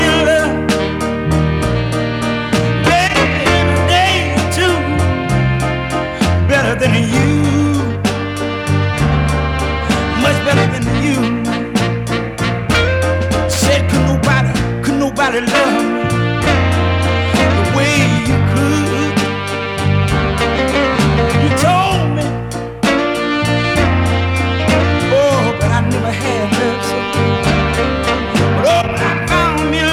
Жанр: R&b / Кантри / Соул